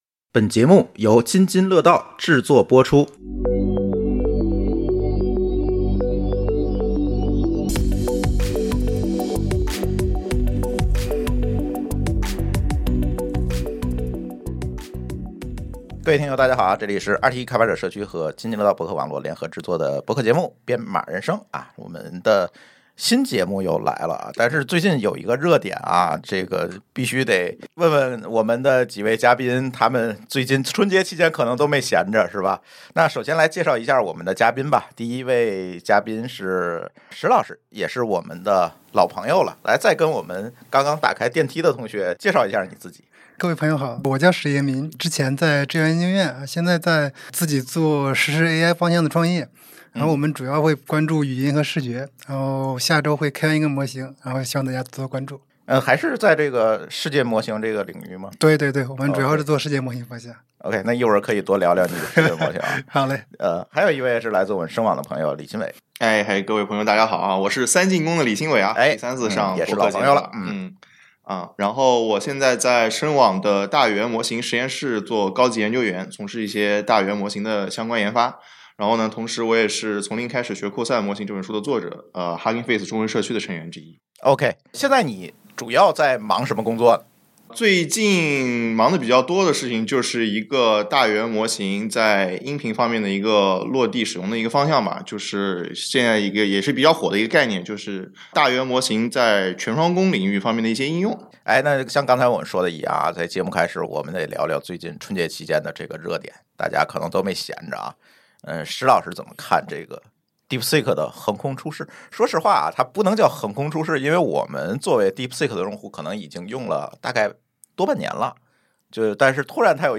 【本期嘉宾和主播】